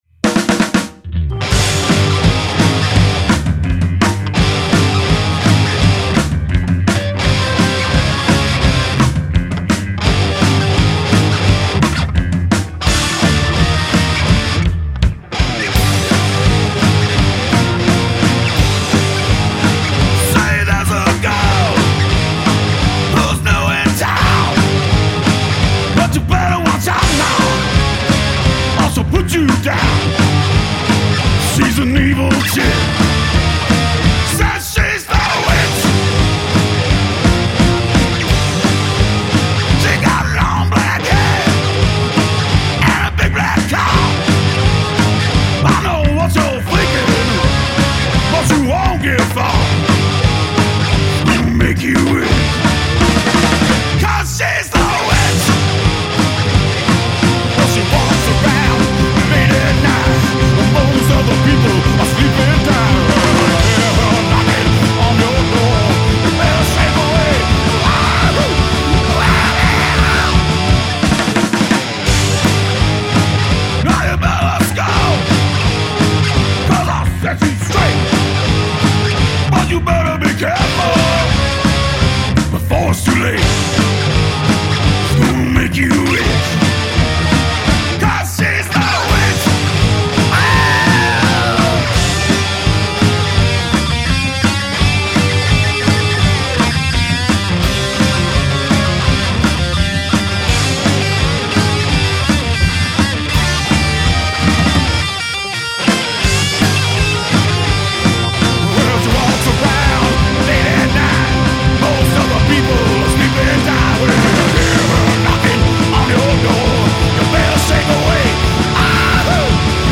This week we get ready for Halloween! It’s our guests Glitter Bats third time as our Halloween episode musical act and we’re quite delighted!